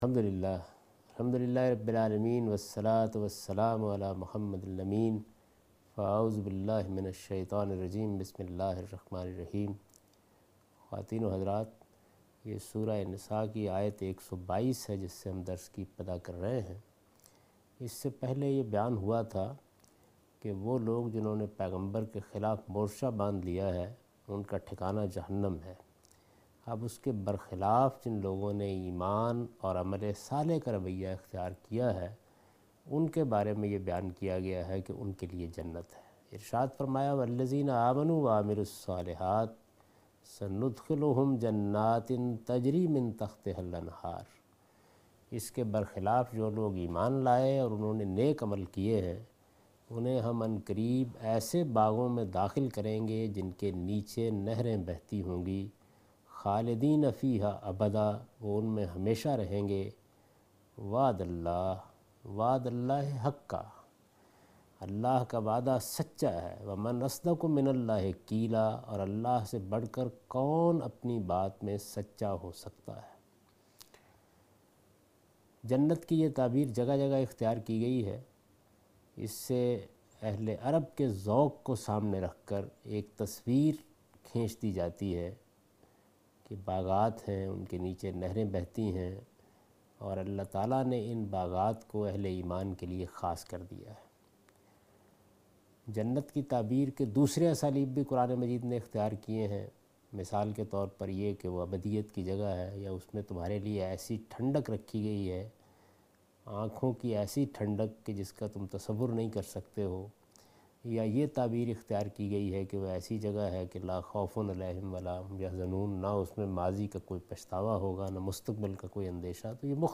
Surah Al-Nisa - A Lecture of Tafseer ul Quran Al-Bayan by Javed Ahmed Ghamidi